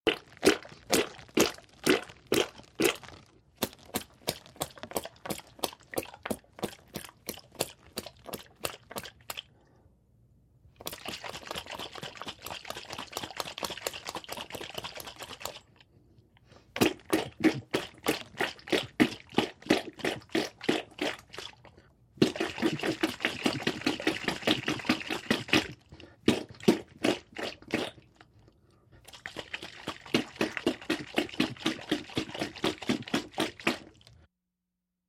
На этой странице собраны звуки кетчупа, майонеза и других соусов — от хлюпающих до булькающих.
Взбалтываем соус в прозрачной бутылке